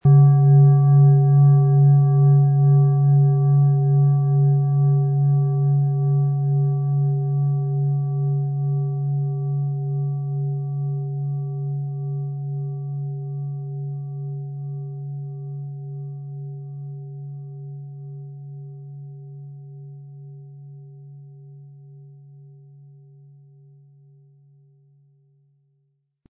• Tiefster Ton: Uranus
• Höchster Ton: DNA
Unter dem Artikel-Bild finden Sie den Original-Klang dieser Schale im Audio-Player - Jetzt reinhören.
Mit einem sanften Anspiel "zaubern" Sie aus der Merkur mit dem beigelegten Klöppel harmonische Töne.
PlanetentöneMerkur & Uranus & DNA (Höchster Ton)
MaterialBronze